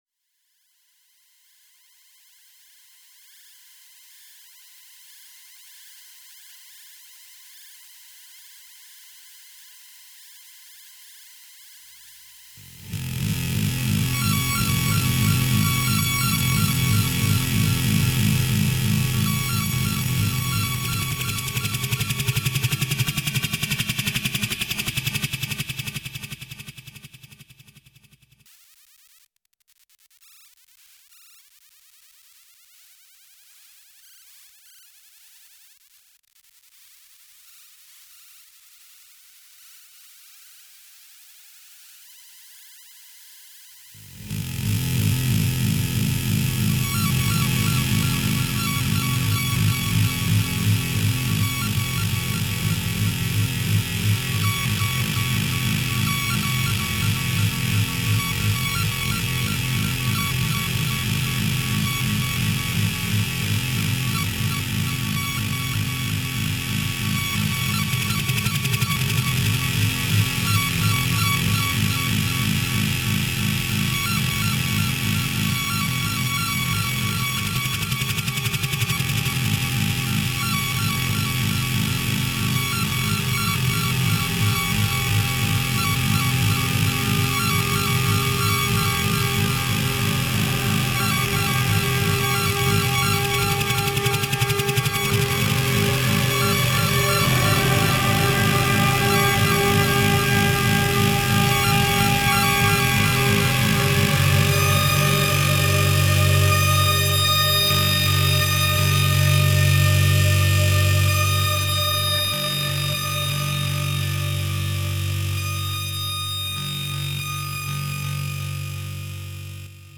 vox insecta, then go away and make one of your own.